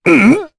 Gau-Vox_Damage_jp_02.wav